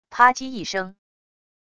啪唧一声wav音频